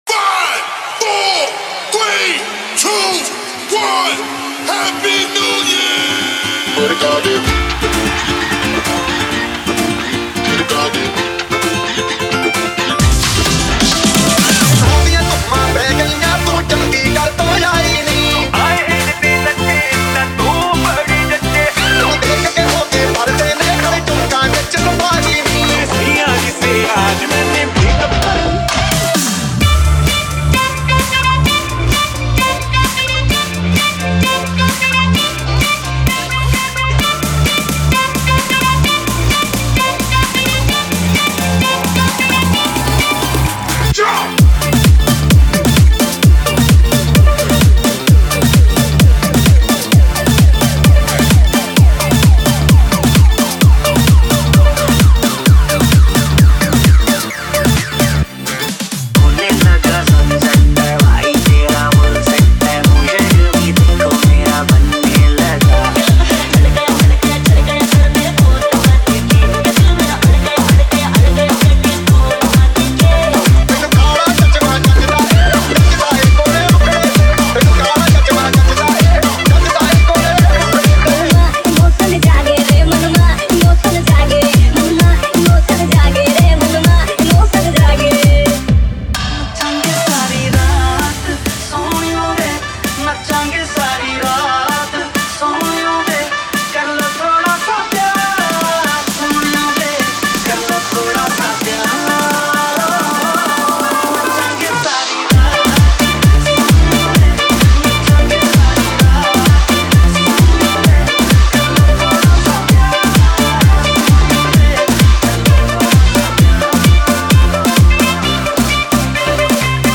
New Year Special Dj Remix Songs Download